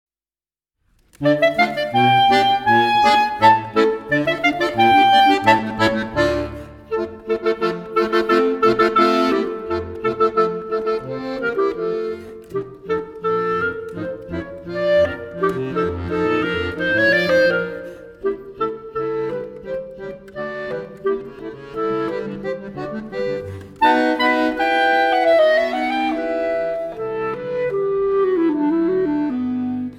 mix popular adn scholarly types of music.
a contemporary duet for clarinet and accordion